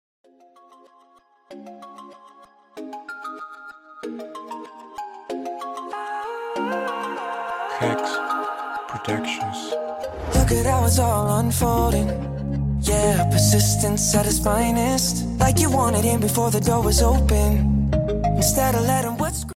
Esto es un MASHUP.